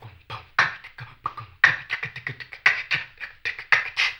HAMBONE 16-L.wav